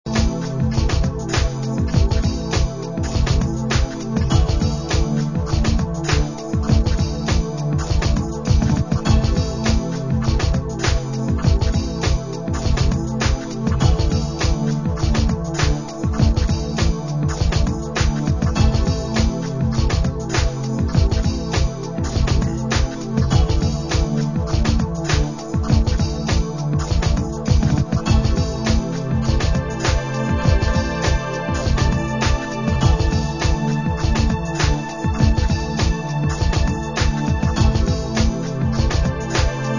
Segunda maqueta con tonos electro-rock bailables.